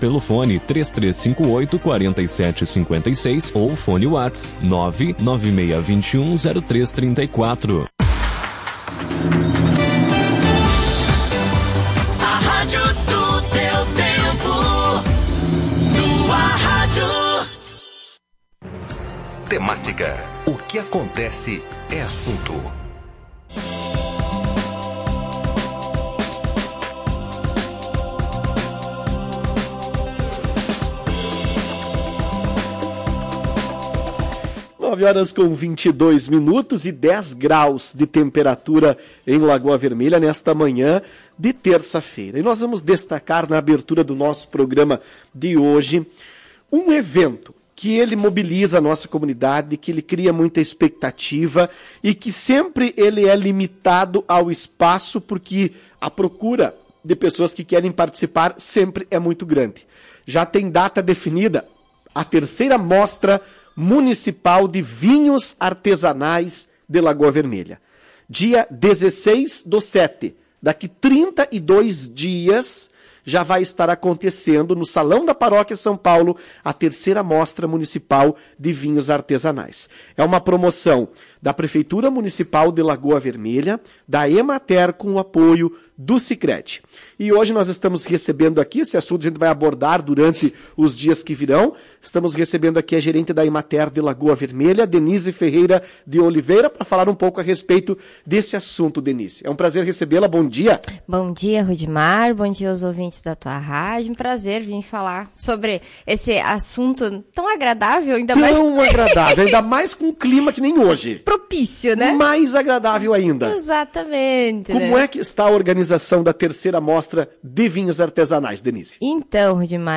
Em entrevista à Tua Rádio Cacique